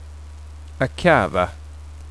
Skånsk ordlista med ljudexempel
långt a, [a:] blir ibland ao eller auo. Det finns två långa a-ljud [a:] som i "bra" och [a:] som i "akava"